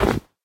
Minecraft / dig / snow1.ogg
snow1.ogg